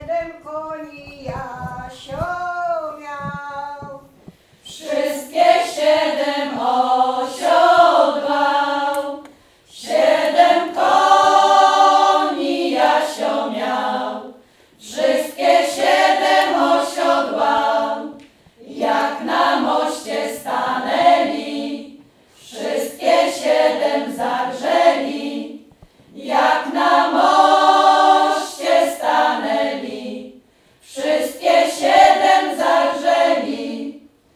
W niedzielę, 20 września, w Sali Kameralnej odbył się koncert tradycyjnych pieśni w wykonaniu zespołów ludowych, przy współudziale osób kultywujących ten gatunek muzyki.
Zabrzmiały zatem tradycyjne pieśni z naszego regionu – tęskne i radosne, mówiące o uczuciach, czasem szczęśliwych, czasem nietrafnie ulokowanych, o codziennym życiu, trudach pracy na roli, radościach i smutkach, ale też pięknie krajobrazu.
Wigranki z uczestnikami warsztatów: